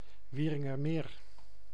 Wieringermeer (Dutch pronunciation: [ˈʋiːrɪŋərˌmeːr]
Nl-Wieringermeer.ogg.mp3